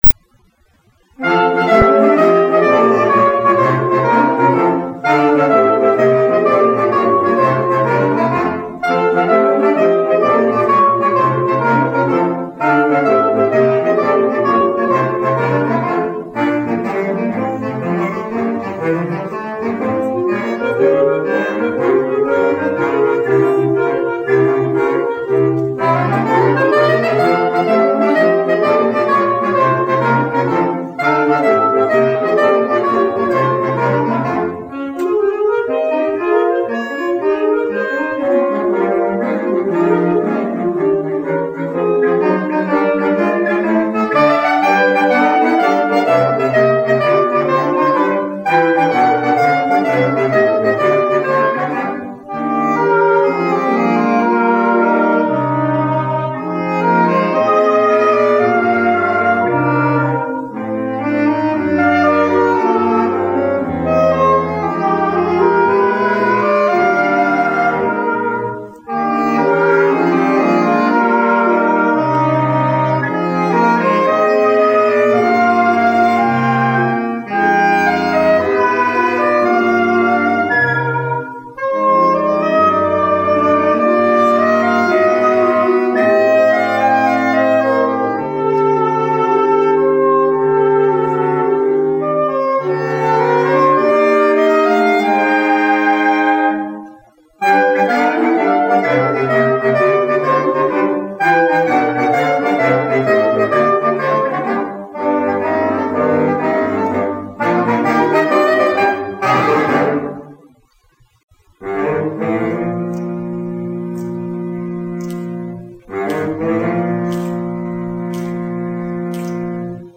Houston Pride Band - 25th Anniversary Concert.mp3